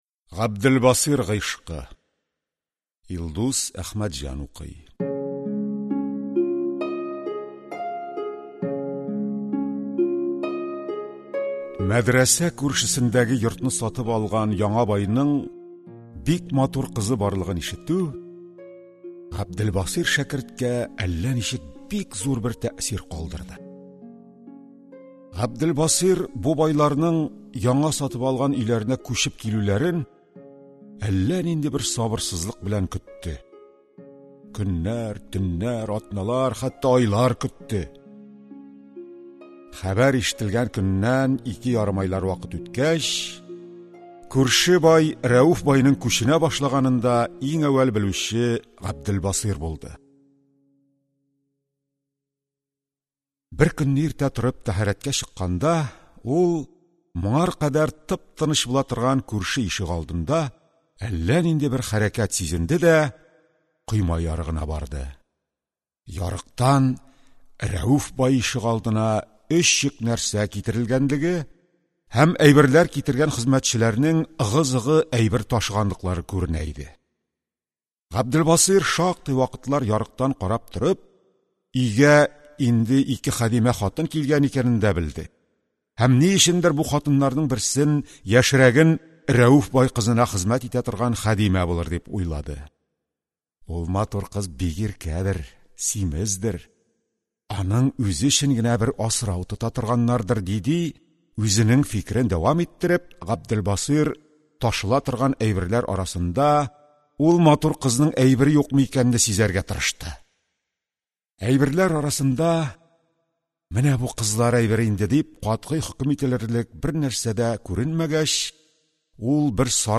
Аудиокнига Габделбасыйр гыйшкы | Библиотека аудиокниг